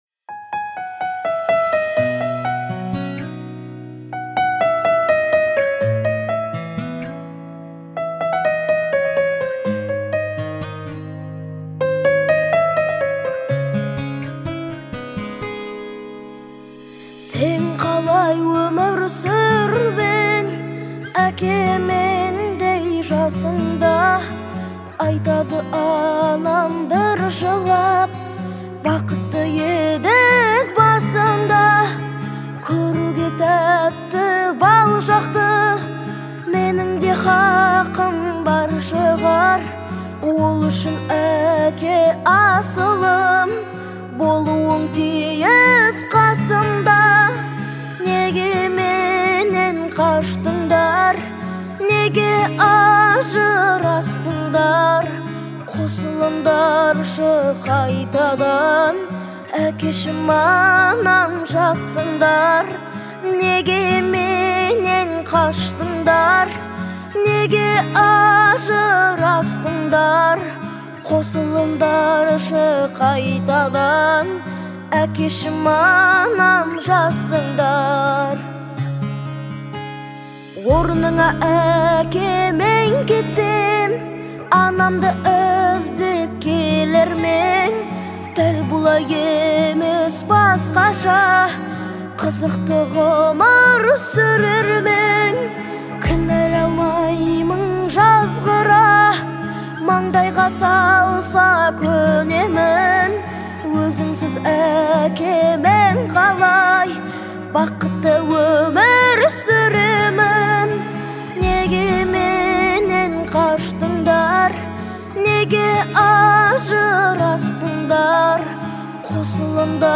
это трогательная баллада в жанре поп